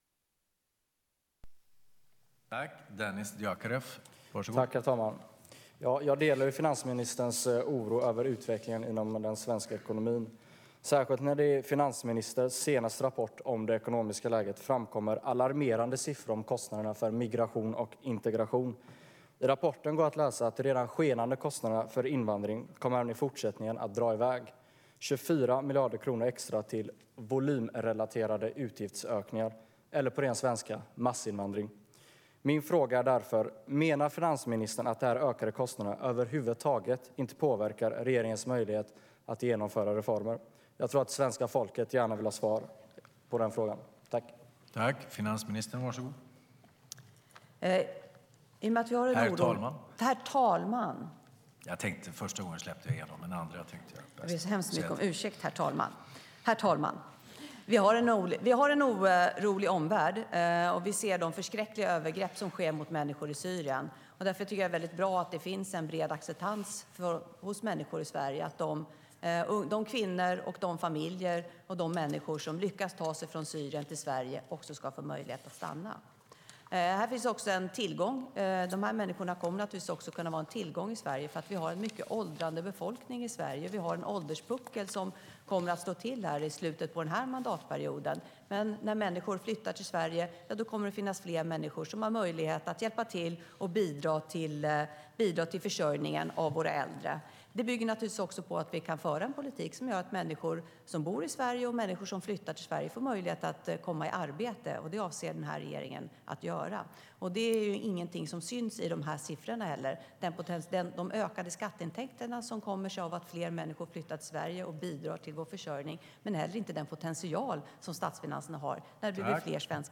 I en riksdagsdebatt den 22:a januari ställde riksdagens yngsta ledamot, Dennis Dioukarev (SD) den relevanta frågan till Magdalena ”Ladorna är tomma” Andersson, om svensk migrationspolitik förhindrar regeringens möjligheter till nya och viktiga satsningar.
dioukarev_andersson.mp3